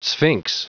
Prononciation du mot sphinx en anglais (fichier audio)
Prononciation du mot : sphinx